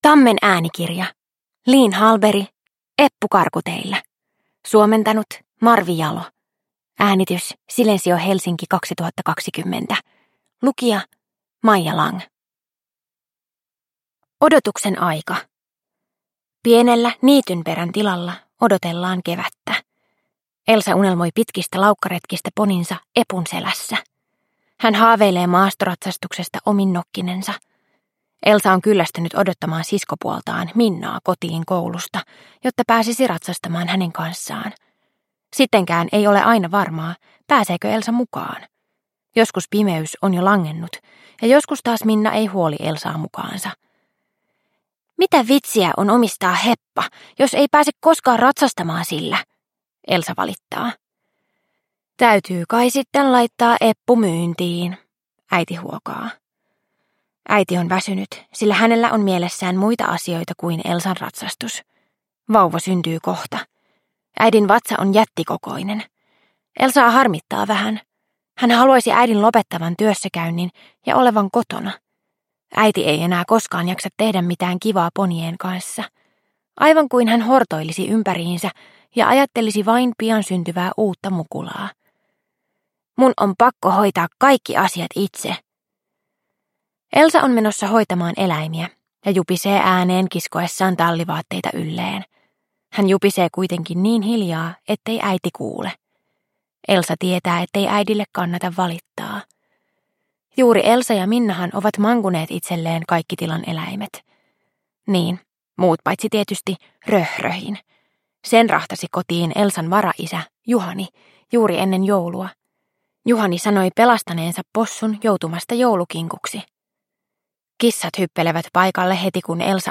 Eppu karkuteillä – Ljudbok – Laddas ner